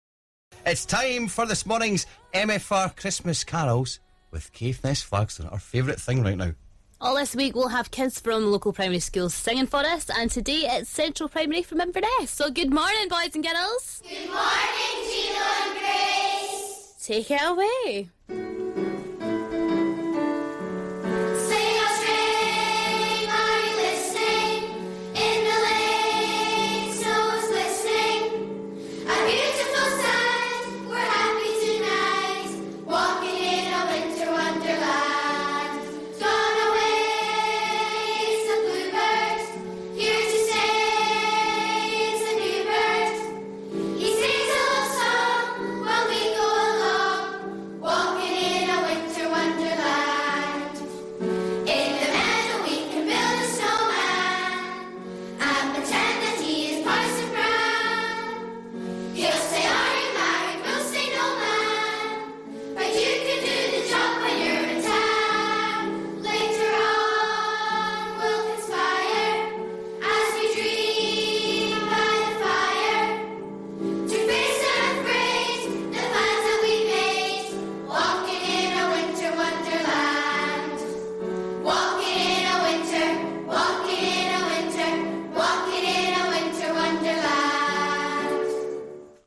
This Christmas we have 5 local primary school choirs performing a Christmas song on MFR all thanks to Caithness Flagstone.